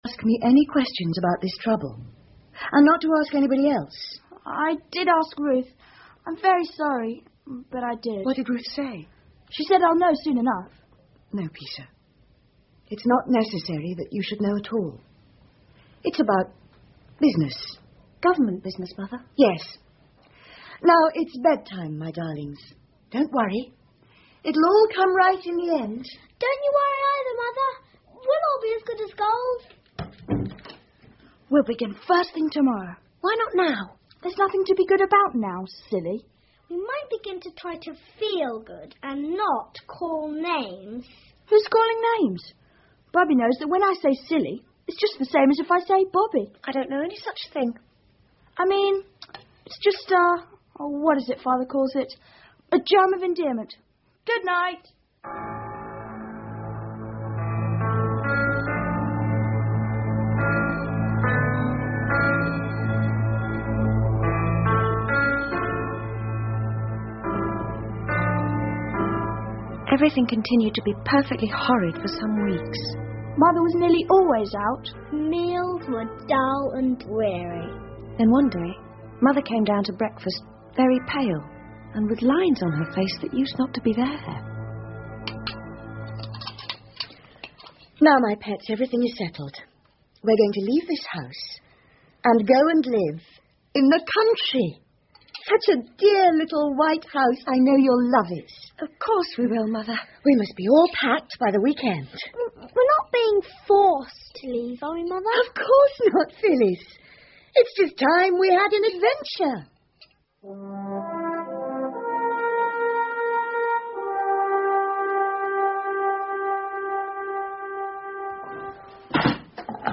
铁道少年 The Railway Children 儿童广播剧 3 听力文件下载—在线英语听力室